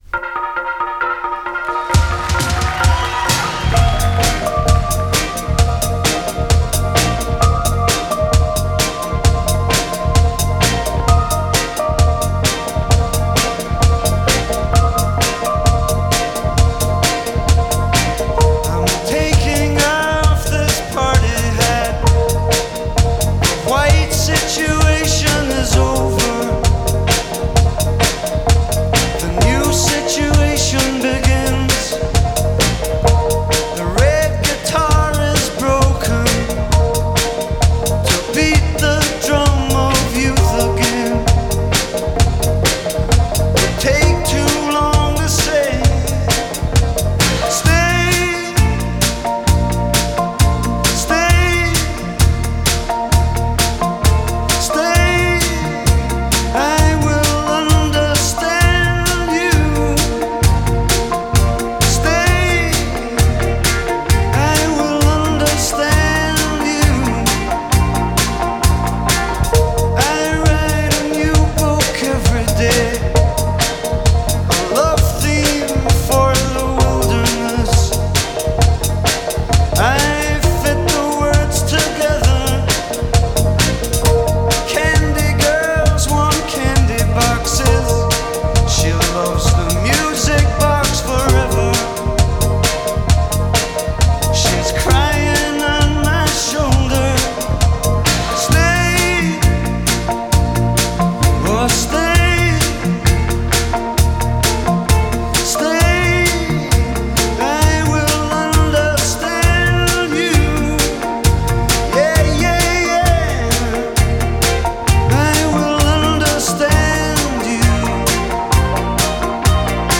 recorded live at the Manchester Trade Hall
A sublime aura of melancholia.